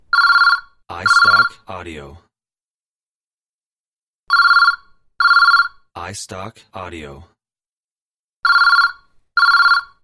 Phone Ring #3
Digitally recorded of desktop phone ring sound.